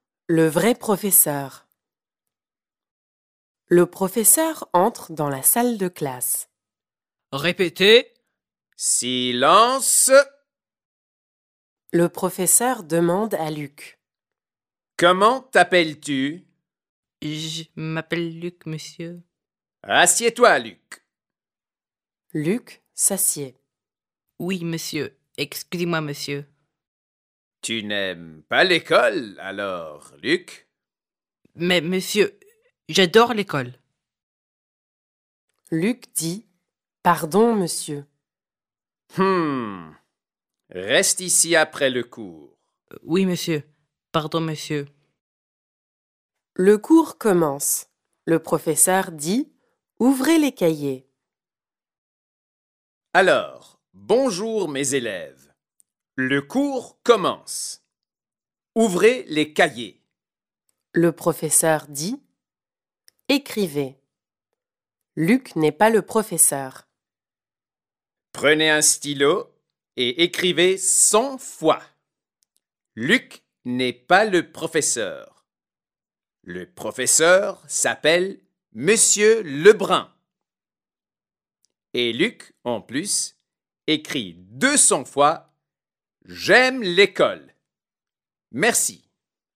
Listen to the story 'Le vrai professeur' performed by native French speakers